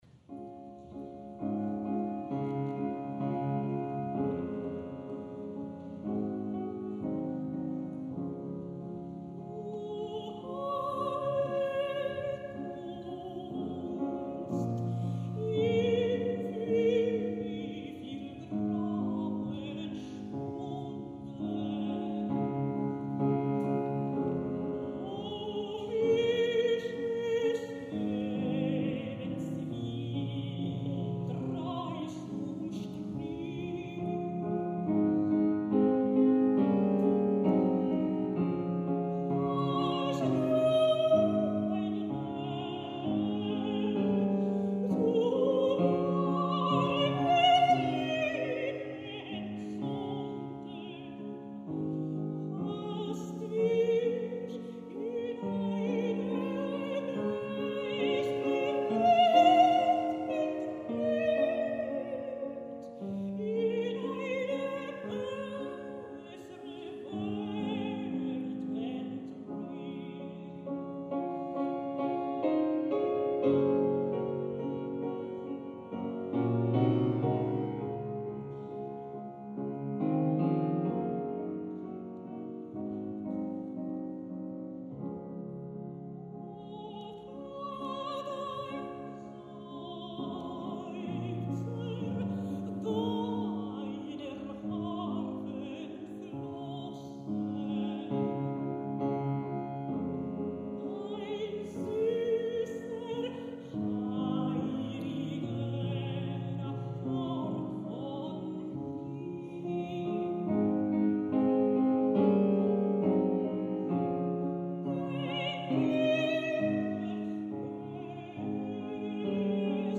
el dia de la inauguració de l’emissora en el primer programa que va transmetre